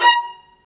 Index of /90_sSampleCDs/Roland LCDP13 String Sections/STR_Violas Marc/STR_Vas3 Marcato